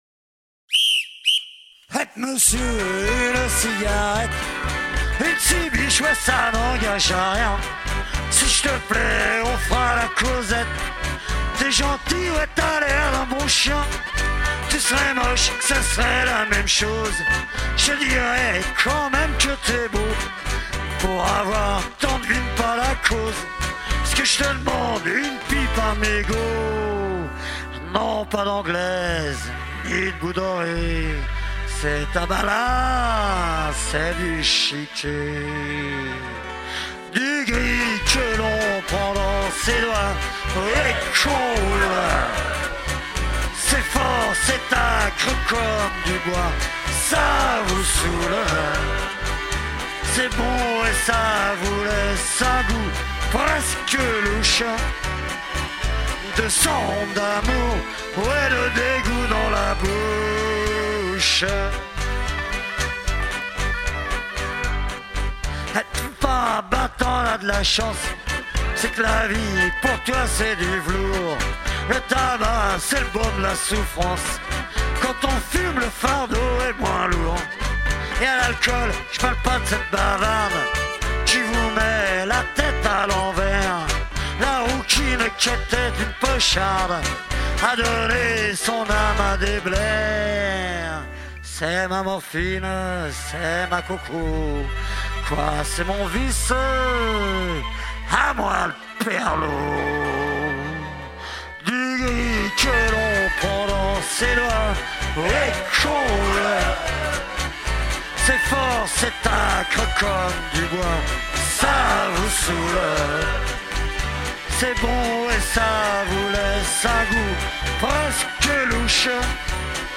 batterie
basse
guitare
Tout ça dans une ambiance amicale et festive.